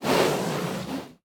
Minecraft Version Minecraft Version 1.21.5 Latest Release | Latest Snapshot 1.21.5 / assets / minecraft / sounds / mob / panda / aggressive / aggressive4.ogg Compare With Compare With Latest Release | Latest Snapshot
aggressive4.ogg